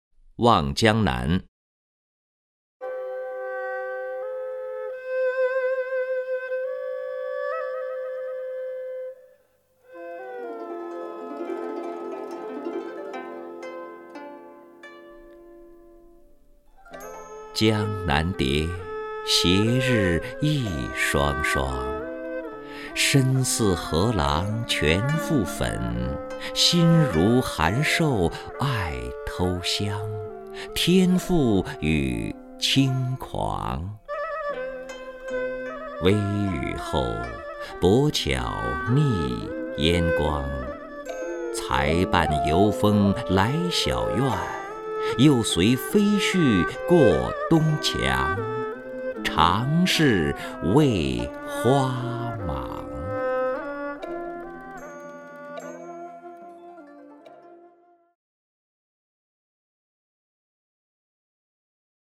任志宏朗诵：《望江南·江南蝶》(（北宋）欧阳修)　/ （北宋）欧阳修